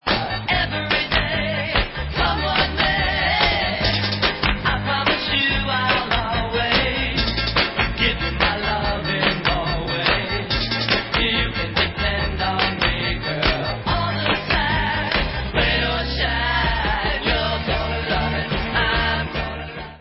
sledovat novinky v oddělení Pop/Oldies